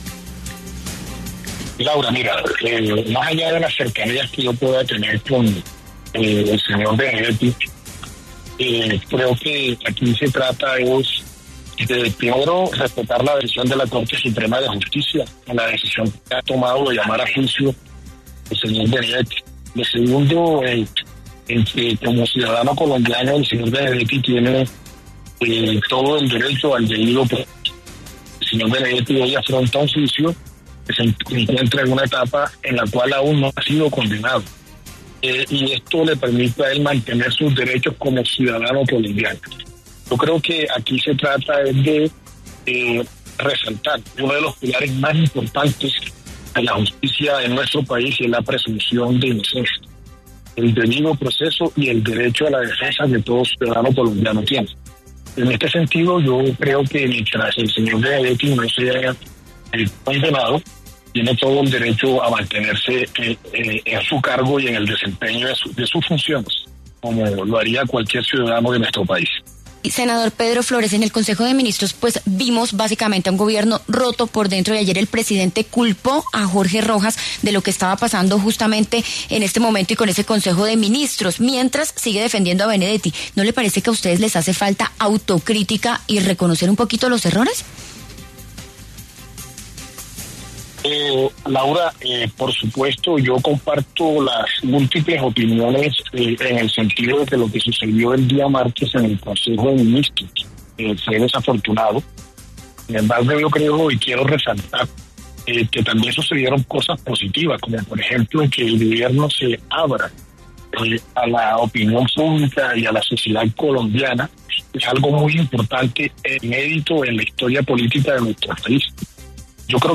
El senador Pedro Flórez, del Pacto Histórico, defendió en La W la permanencia de Armando Benedetti como jefe de despacho del presidente Gustavo Petro, pese a su llamado a juicio por presunto tráfico de influencias en el caso Fonade.